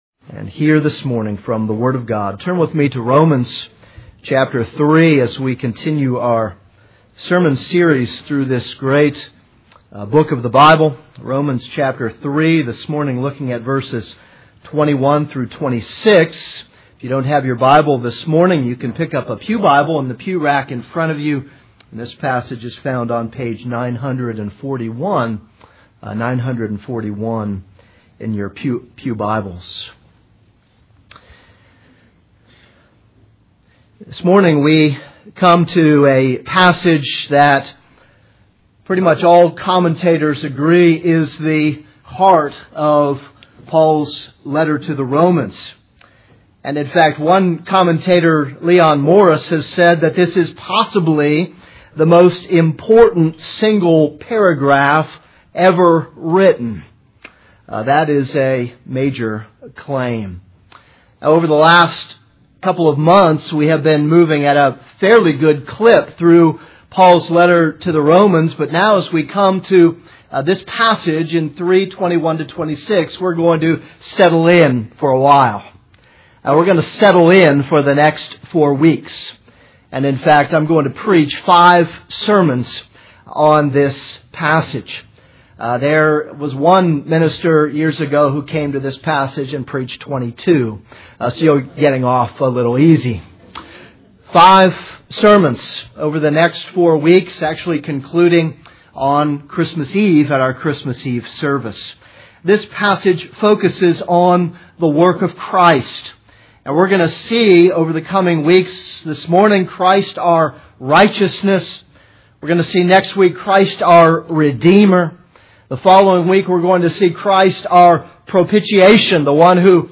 This is a sermon on Romans 3:21-26.